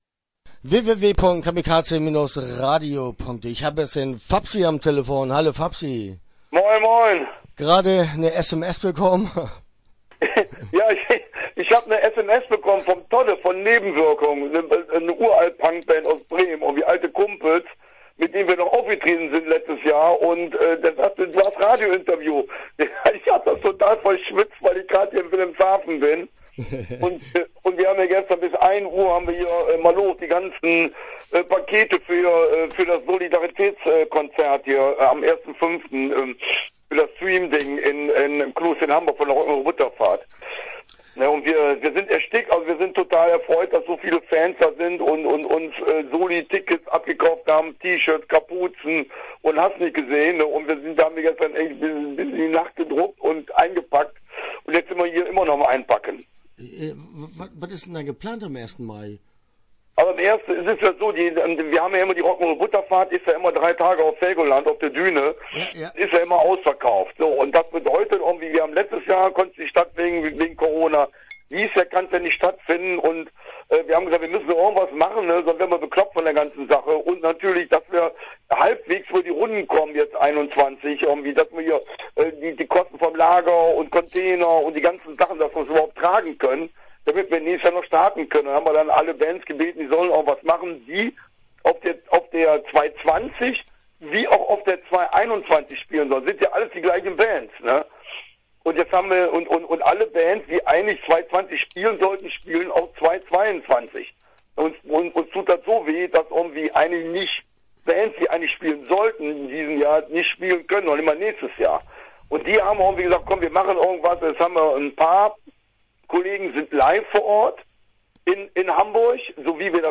Rock`n`roll Butterfahrt - Interview (24:04)